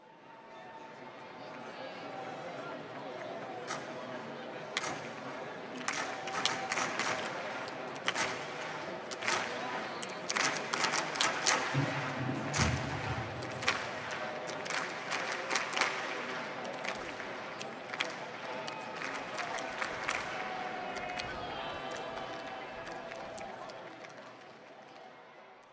clapping.wav